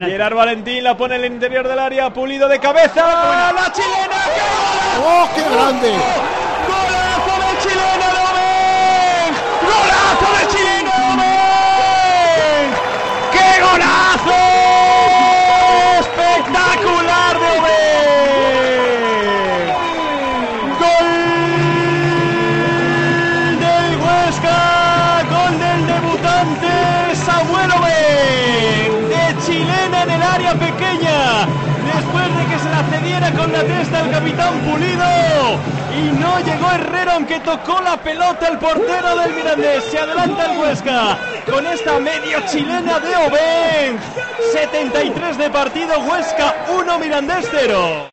04-02-23 Goooolaaaaazooooo de Obeeeeeeeeng!!! Huesca - CD Mirandés (1-0)